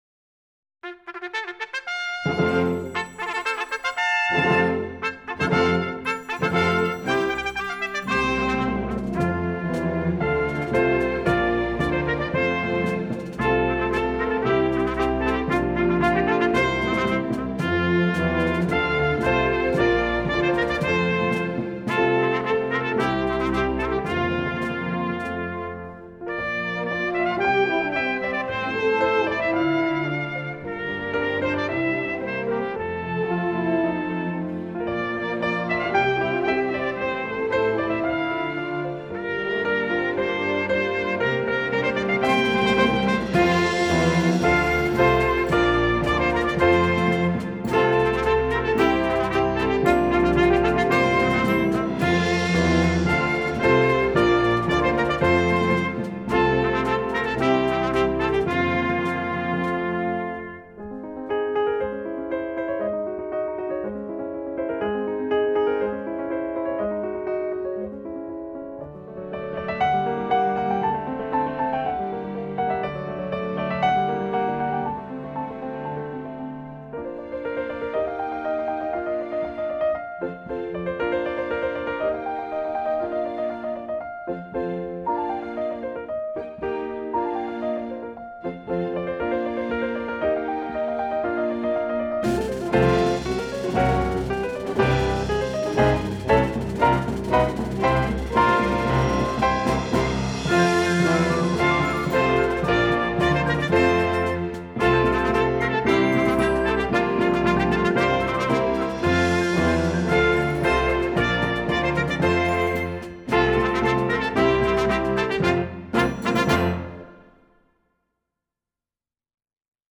is an exhilarating track
Trumpet 1 and 2